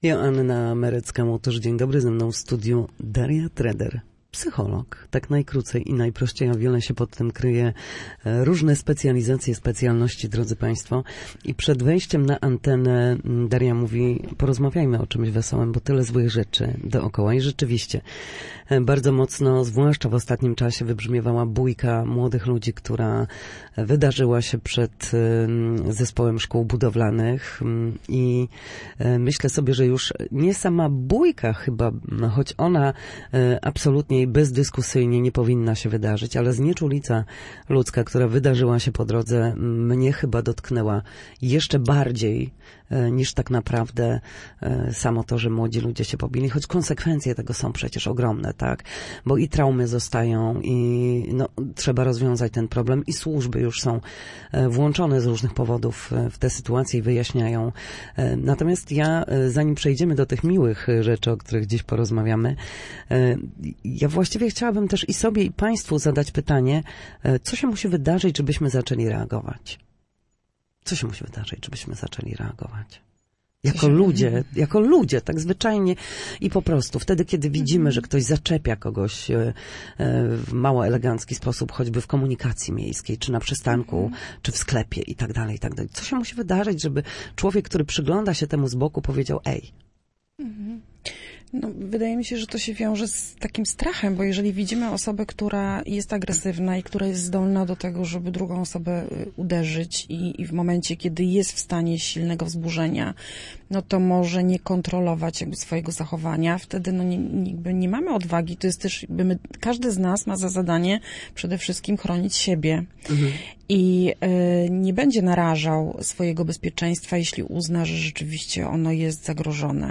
Na naszej antenie odnosiła się do kilku tematów – jak odnaleźć spokój w grudniu, kiedy do świąt zostało niewiele czasu, jak wpływa obojętność na zdarzenia w przestrzeni publicznej oraz jak znaleźć czas i przestrzeń dla siebie i bliskich w natłoku z